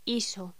Locución: ISO
voz